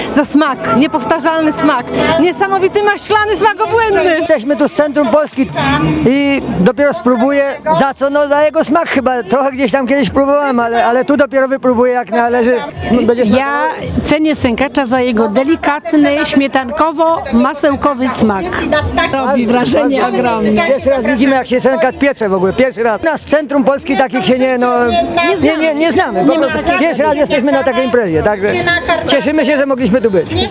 Pieczeniu towarzyszył festyn.
Zapytaliśmy turystów, za co lubią sękacza.